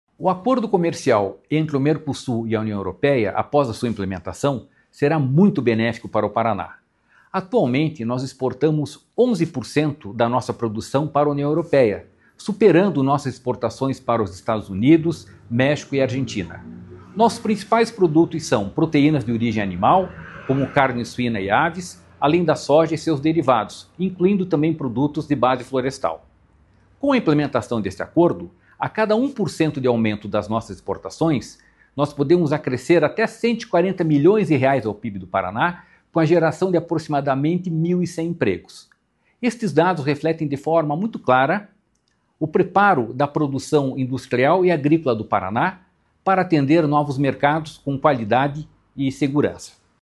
Sonora do presidente do Ipardes, Jorge Callado, sobre como o acordo comercial com a União Europeia pode elevar o PIB do Paraná